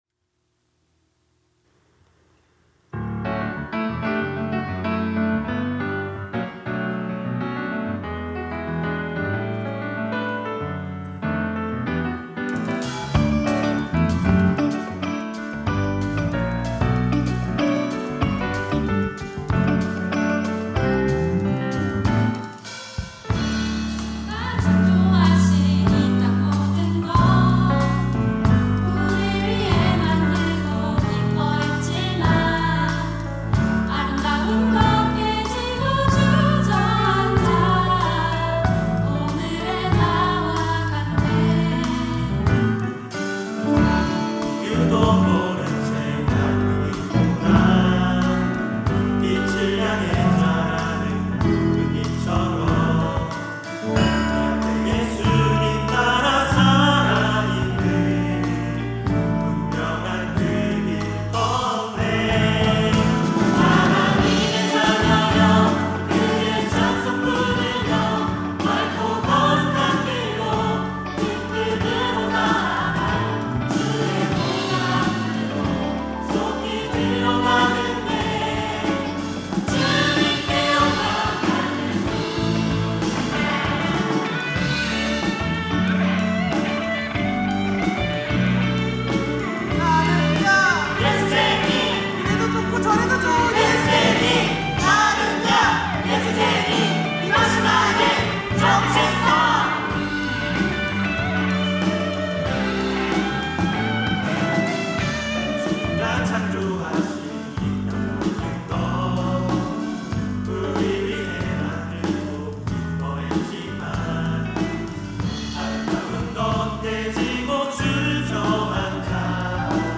특송과 특주 - 빛 (찬송으로 보답할 수 없는)
청년부 1팀 리더쉽